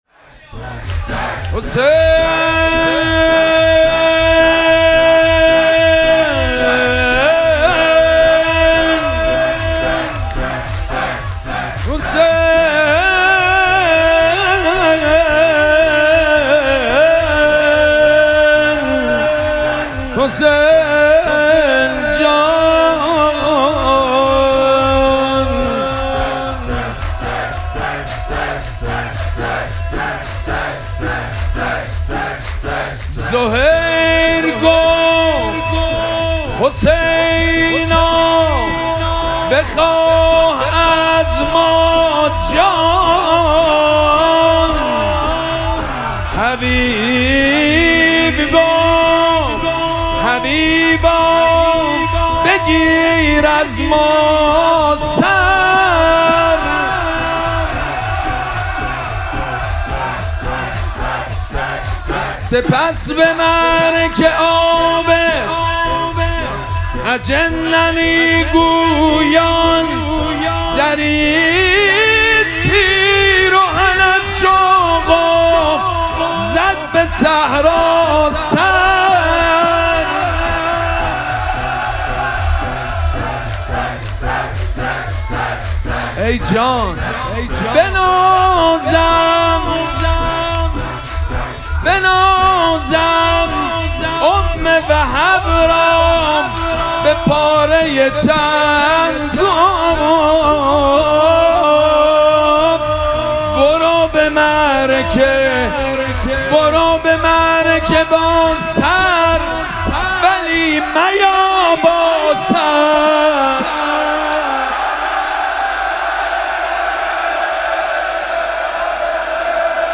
مراسم مناجات شب بیست و سوم ماه مبارک رمضان
نغمه خوانی
مداح
حاج سید مجید بنی فاطمه